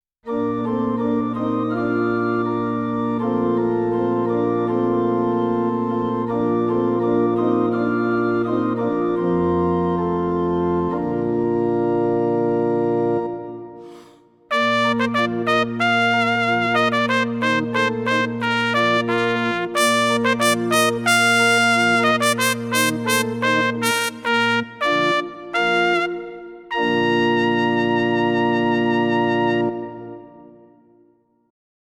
Jeweils zwei Sounds lassen sich übereinander spielen als Layer oder zwischen linker und rechter Hand aufteilen als Split.
Split: Pipe Organ Pricipal + Bright Trumpet
yamaha_csp-170_test_demo05_split_pipeorgan_trumpet.mp3